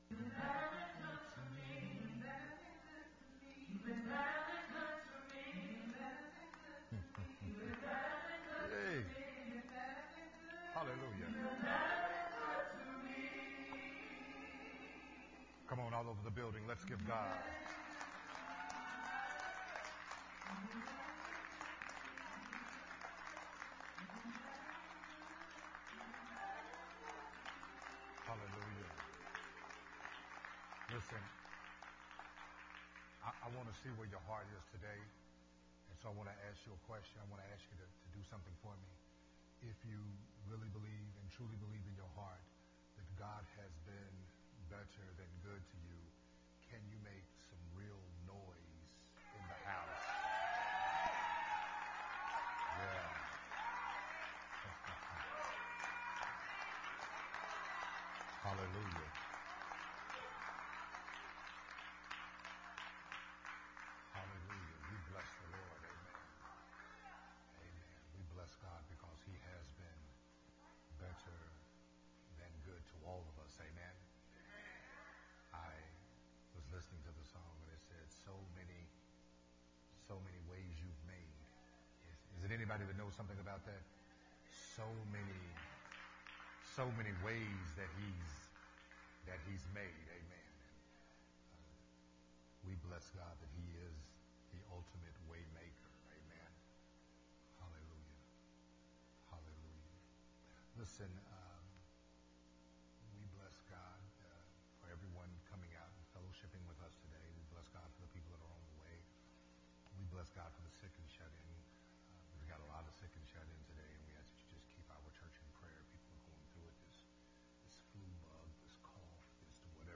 Part 7 of the sermon series
recorded at Unity Worship Center